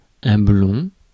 Ääntäminen
Synonyymit doré jaune paille Ääntäminen France: IPA: /blɔ̃/ Haettu sana löytyi näillä lähdekielillä: ranska Käännös 1. блондинка {f} (blondínka) 2. блондин {m} (blondín) 3. рус 4. светъл Suku: m .